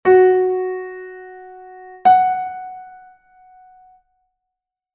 O intervalo fa#3 solb4